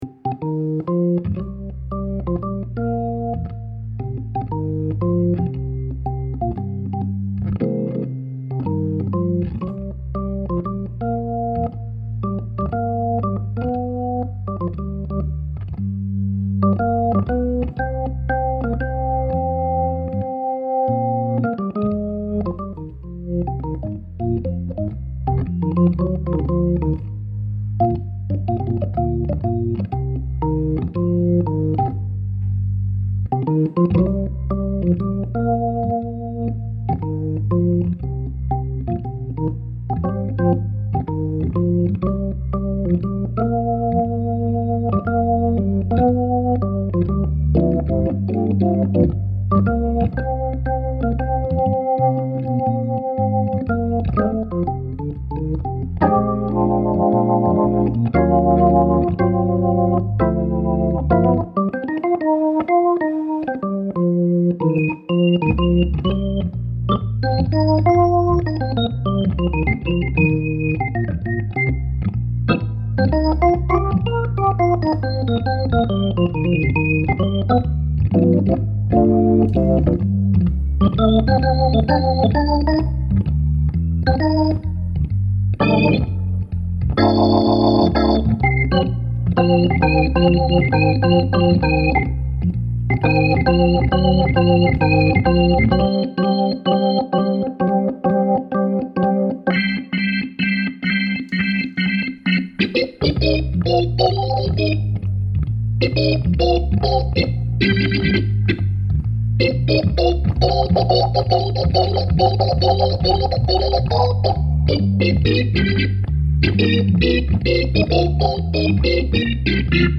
la procedure était simple...enregistrement audio et midi du new B sur la leslie 925 puis ....envoi du midi de la sequence dans le B4 lui meme branché sur la meme cabine avec la pedale combo trek II...le B4 était regle sur la line box ..logique puisqu'envoyé dans une vraie leslie.
le new B3 sur la 925
Y'a "rien qui déborde" et je préfère le NewB de ce côté là.